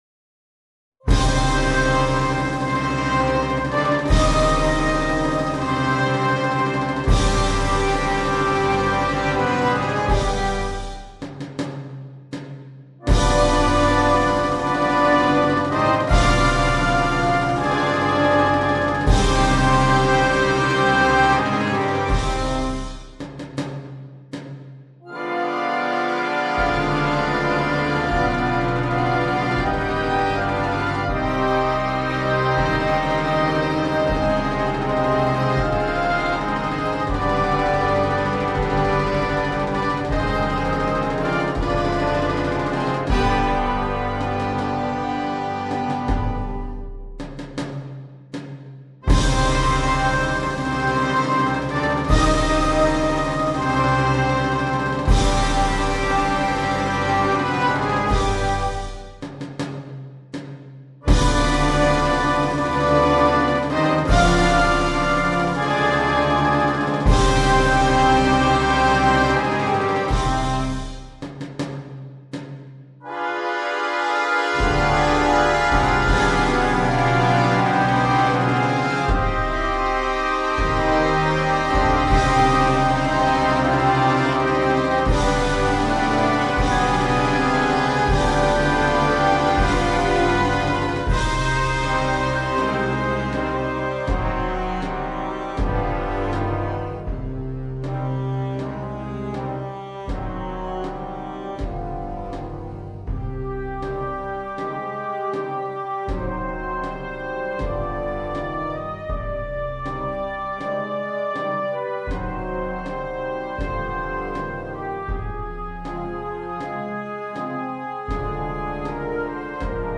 Marcia funebre